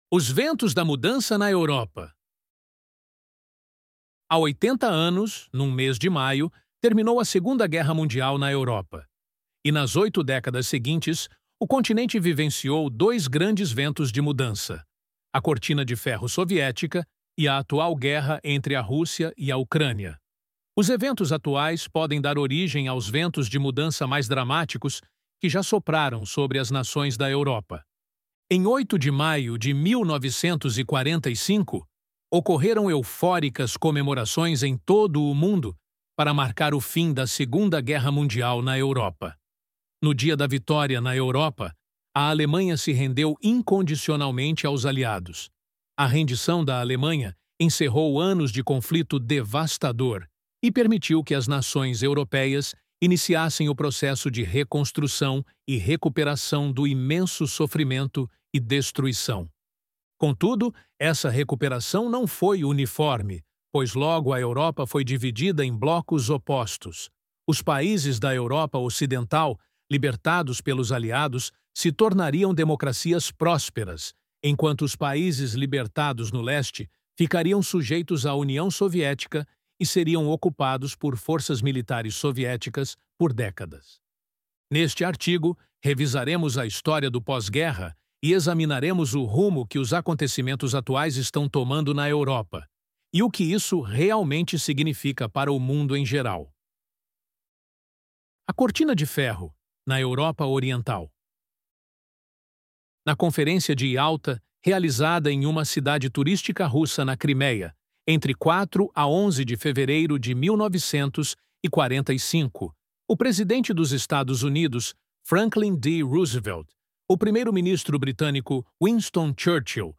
ElevenLabs_Os_Ventos_da_Mudança_na_Europa.mp3